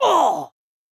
damage_flying.wav